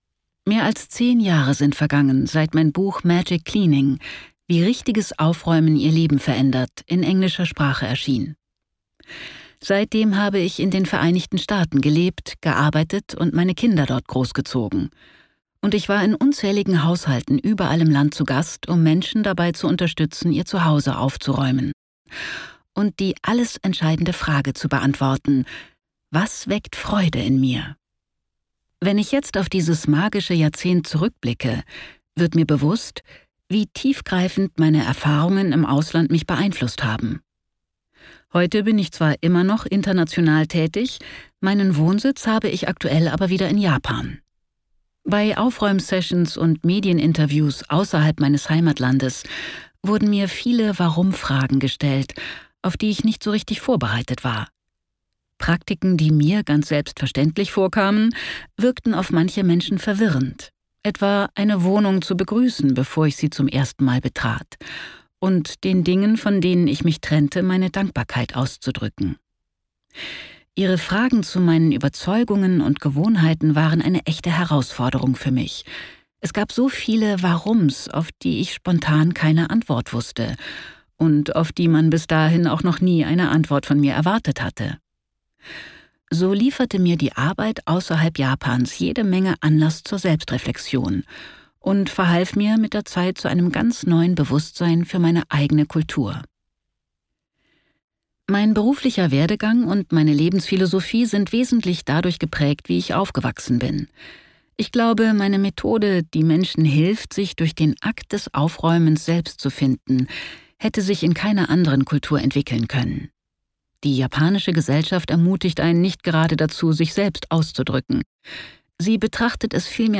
Sechs japanische Prinzipien, die mein Leben begleiten | Das persönlichste Hörbuch der Bestsellerautorin
Gekürzt Autorisierte, d.h. von Autor:innen und / oder Verlagen freigegebene, bearbeitete Fassung.